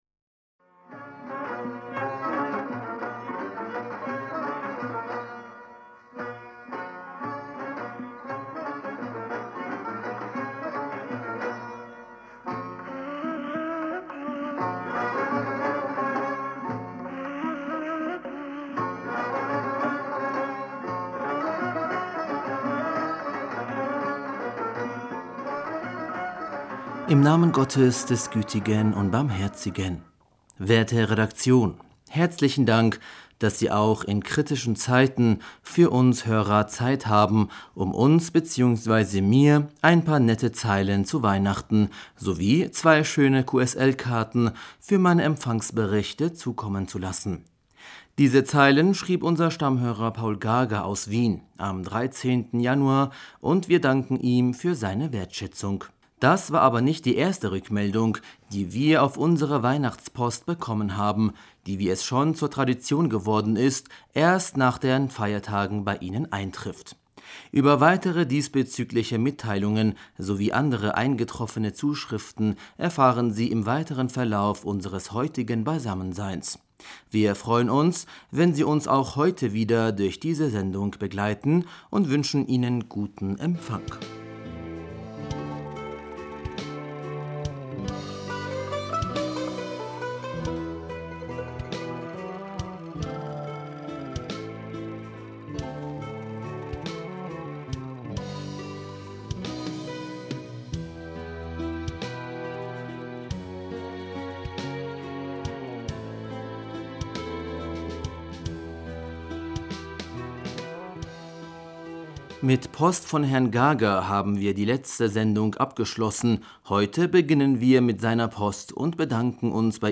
Hörerpostsendung am 26. Januar 2020 Bismillaher rahmaner rahim - „Werte Redaktion!